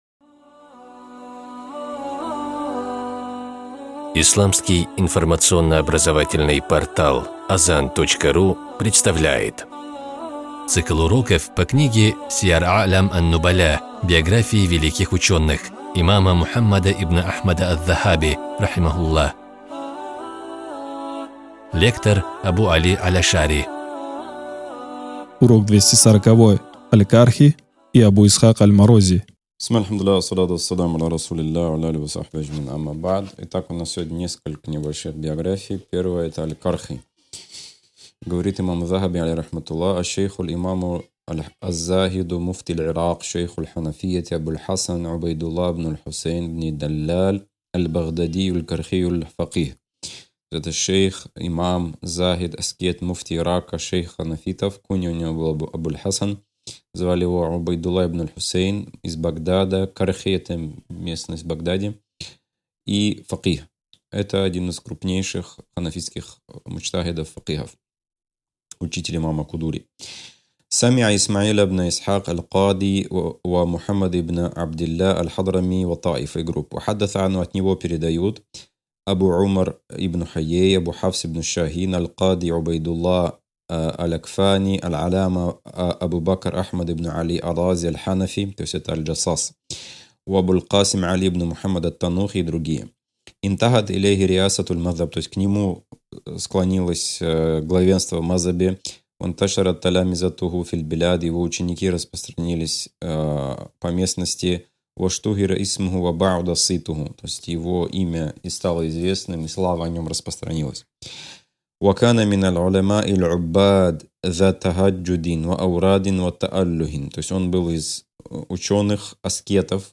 С позволения Всевышнего Аллаха, мы начинаем выкладывать аудио-уроки по книге имама Мухаммада ибн Ахмада Аз-Захаби, рахимахуллах, «Сияр а’лям ан-Нубаля» (биографии великих ученых).